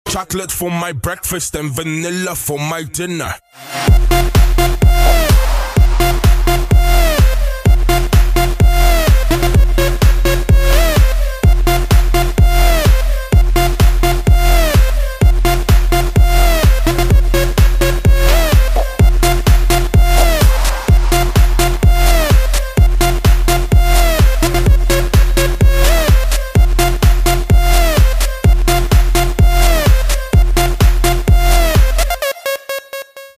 • Качество: 128, Stereo
мужской голос
громкие
dance
Electronic
EDM
электронная музыка
club
electro house